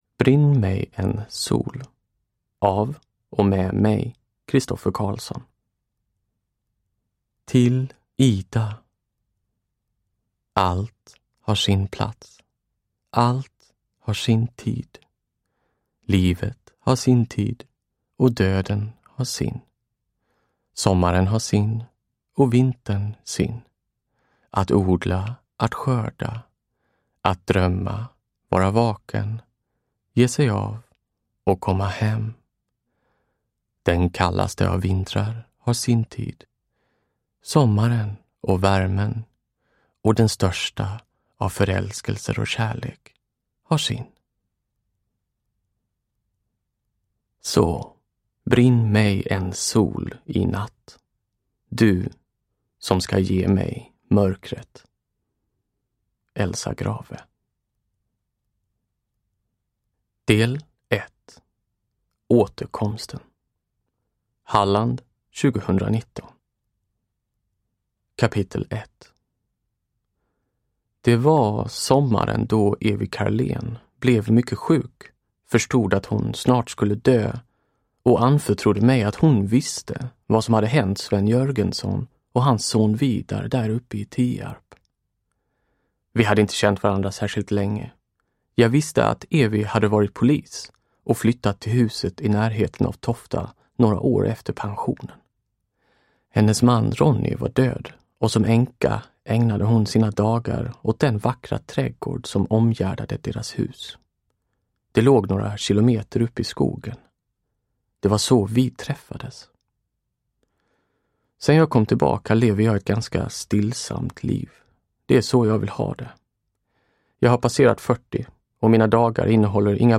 Uppläsare: Christoffer Carlsson
Brinn mig en sol – Ljudbok – Laddas ner